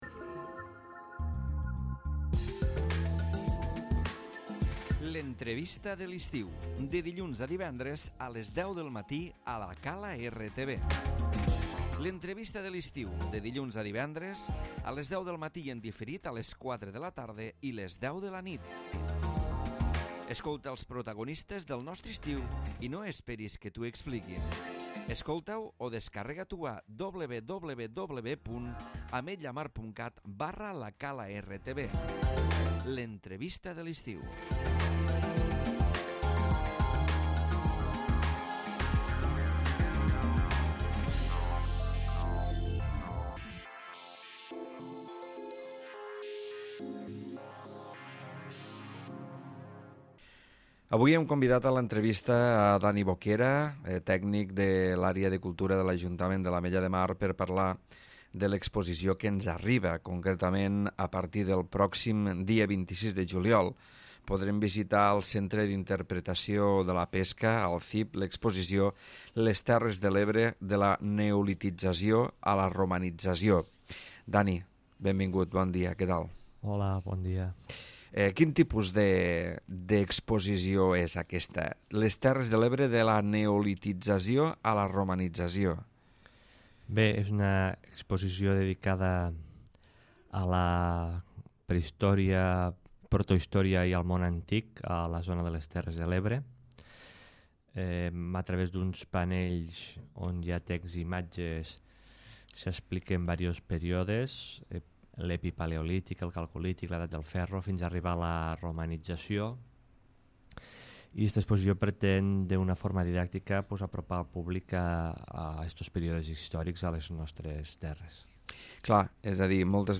L'Entrevista de l'Estiu